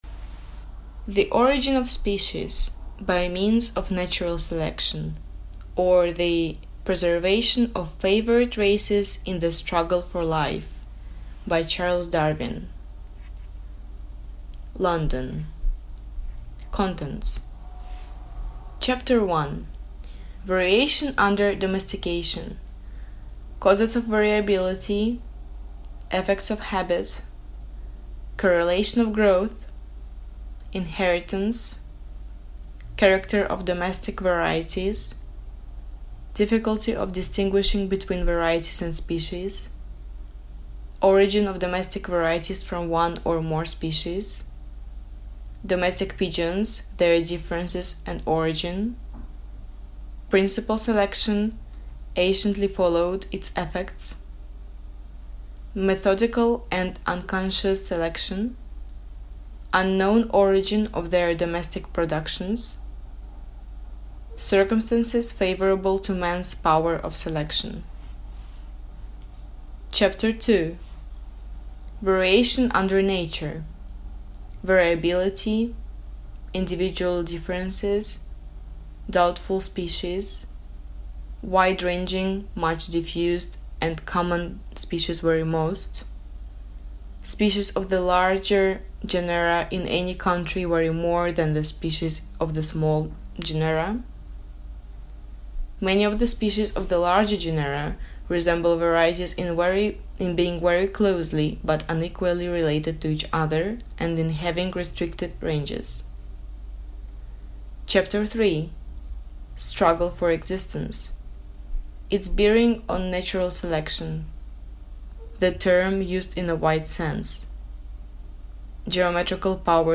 Аудиокнига On the Origin of Species | Библиотека аудиокниг